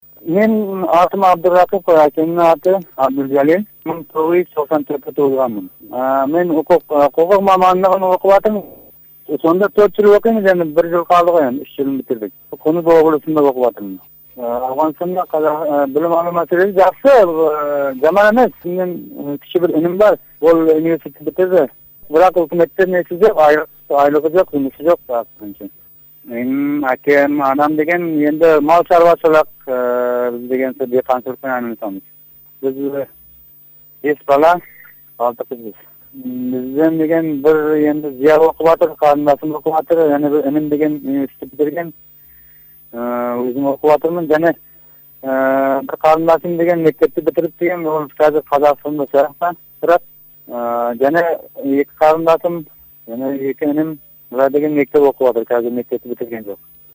сұхбатынан үзінді